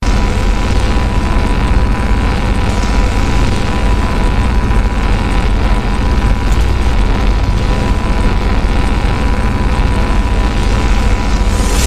AmbienceSeekOld.mp3